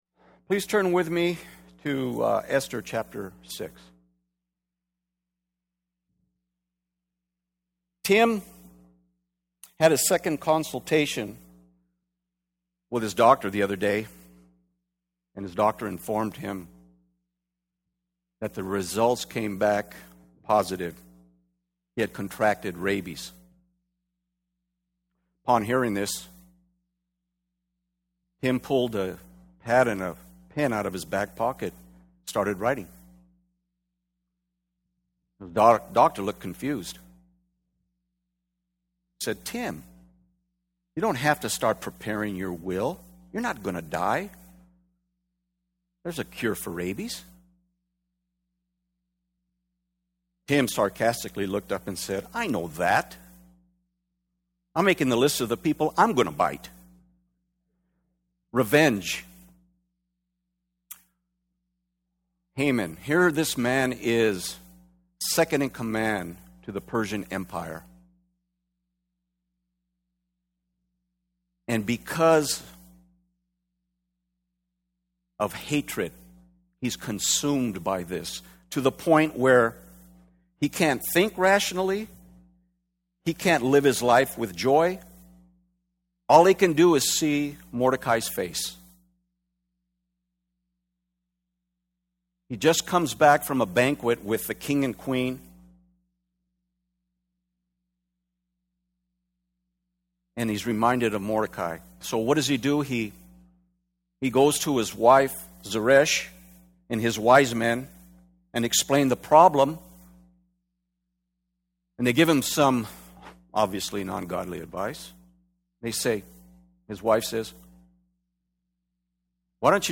Esther 6:1-14 Service: Men's Bible Fellowship Esther 6 « Stand Fast